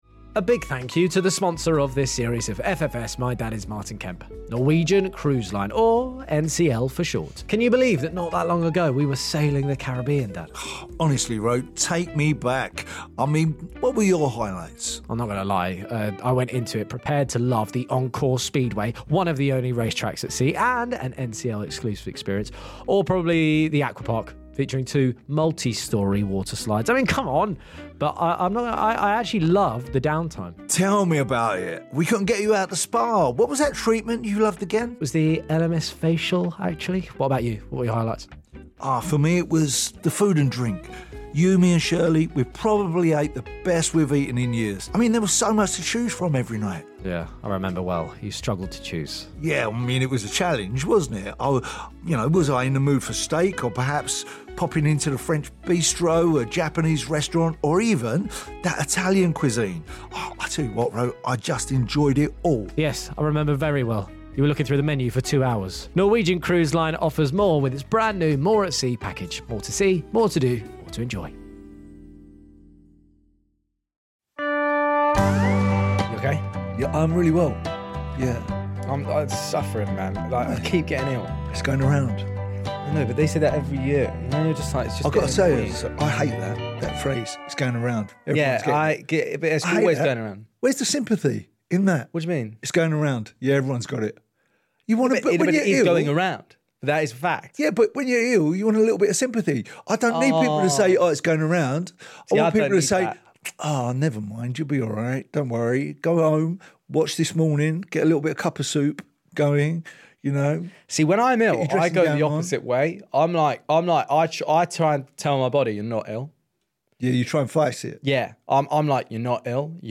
My Dad Is Martin Kemp Roman Kemp & Martin Kemp Comedy 4.9 • 1000 Ratings 🗓 26 February 2025 ⏱ 59 minutes 🔗 Recording | iTunes | RSS 🧾 Download transcript Summary It’s the question on everyone’s minds, what are Martin and Roman’s BIGGEST regrets?! In this week's episode, Martin and Roman chat about regrets in the world of music, regrets in the childhood playground, and we discover the world of cinema could have been totally different if Martin had gotten the role of a certain English spy...